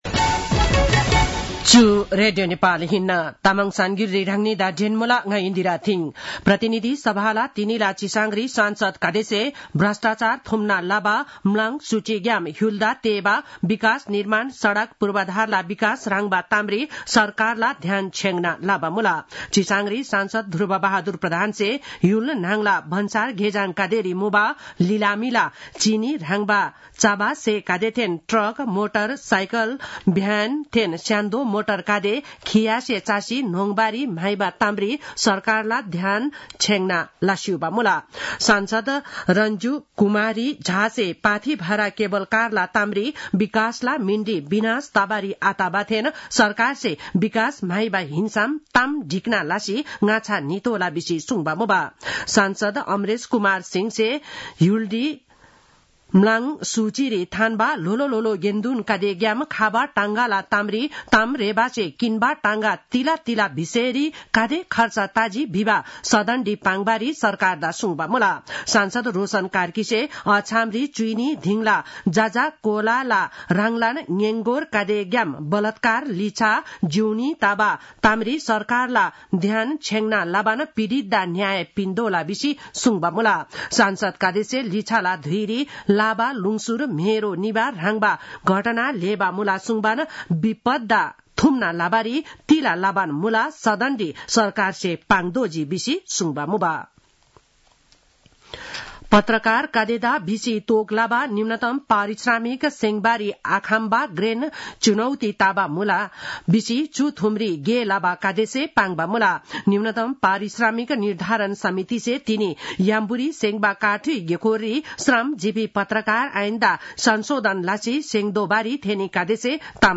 तामाङ भाषाको समाचार : १३ फागुन , २०८१
Tamang-news-11-12.mp3